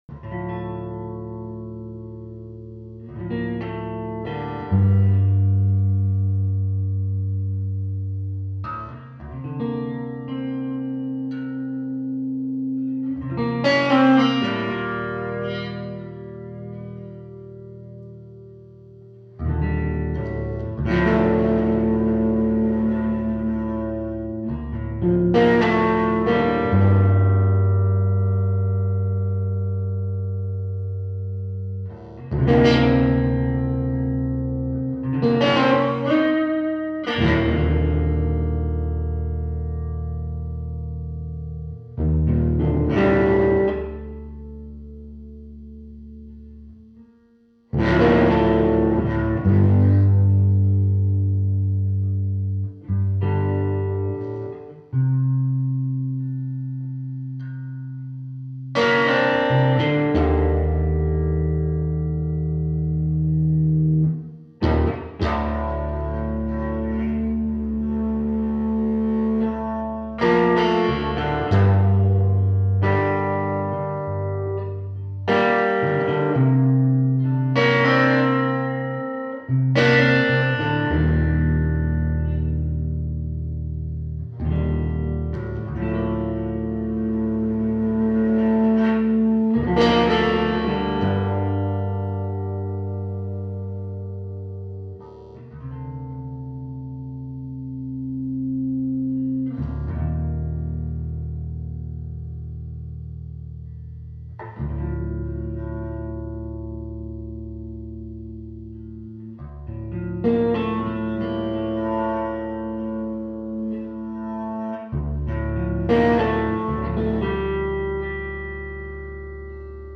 stereo
Live recorded
drums